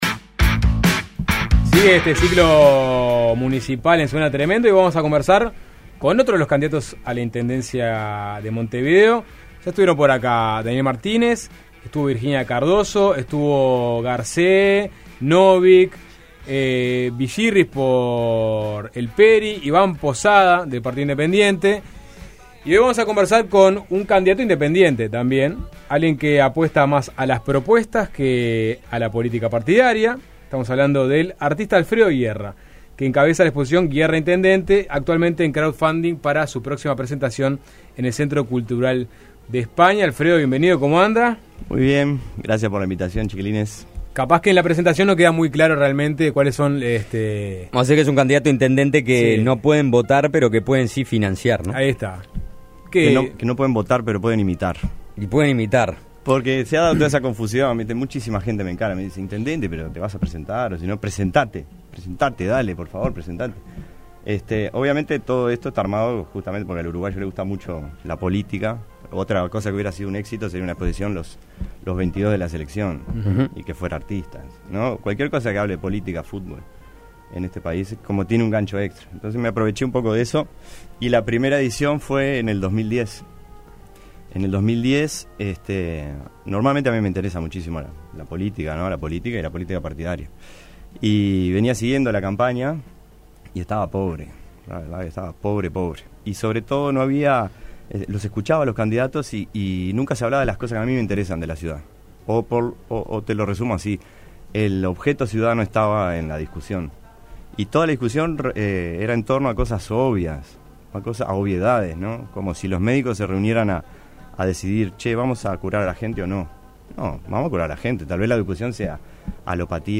Continuamos nuestro ciclo de entrevistas por las elecciones municipales y conversamos con un candidato que pueden apoyar pero no votar: el artista